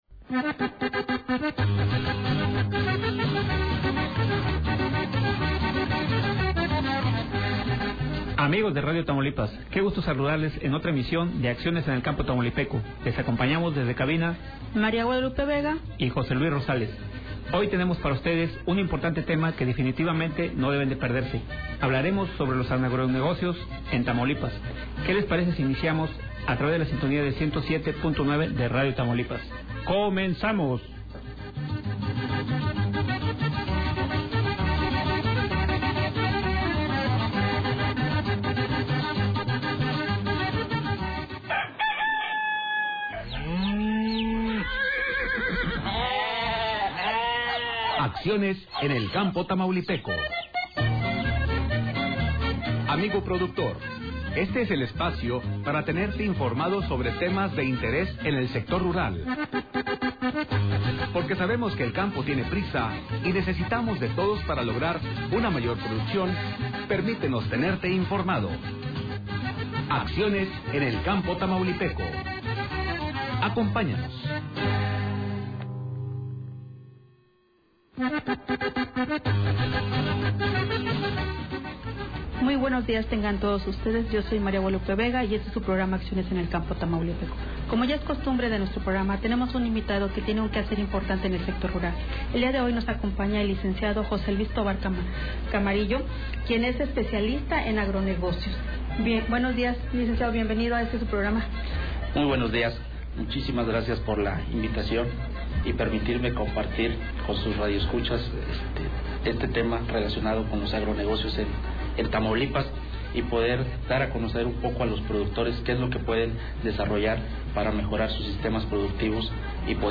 inició la entrevista explicando lo que son los Agronegocios y las actividades productivas que van desde producción primaria, valor agregado y comercialización, de igual forma, señaló la importancia de los Agronegocios en las cadenas productivas, los pasos para empezar a producir, transformar y comercializar sus productos con una visión con enfoque empresarial.